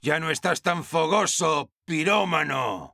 El Sniper sobre el diésel del Pyro